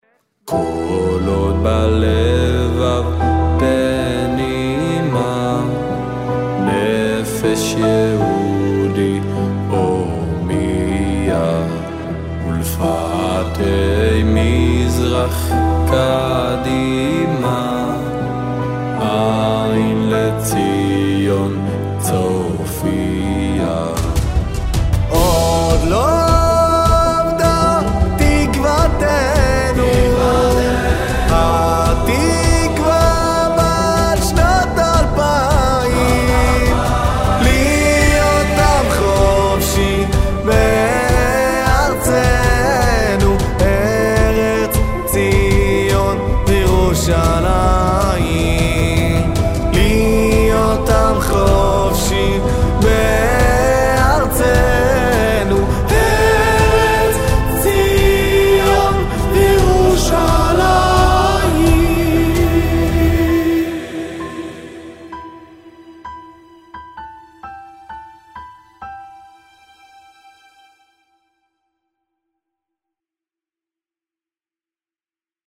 קאבר חדש שעשיתי (התקווה) אשמחח לתגובות
השירה נשמעת מדהים
איכות טובה. המיקס לא מושלם, אבל ממש יפה!
יש לך קול מאוד מאוד יפה!
לגבי האקורדים, ממש לא צורמים כי הרמונית הם נכונים, כלומר אין דיסוננס, ויש גם היפוכים שמראים על הבנה בהרמוניה